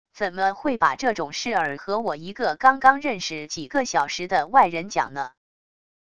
怎么会把这种事儿和我一个刚刚认识几个小时的外人讲呢wav音频生成系统WAV Audio Player